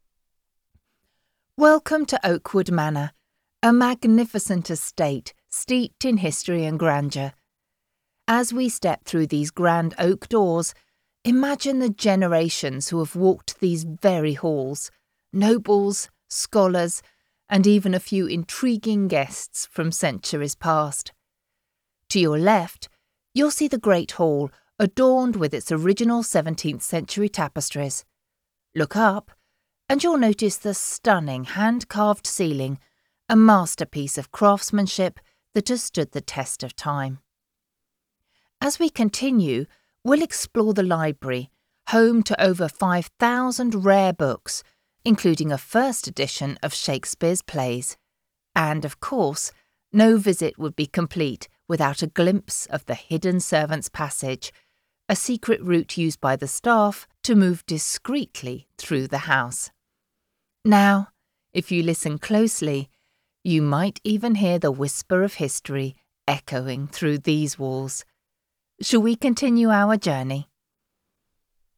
Female
I offer an RP British voice with excellent diction and an expressive, nuanced delivery.
My voice is warm, clear, and naturally engaging.
Example Guided Tour Recording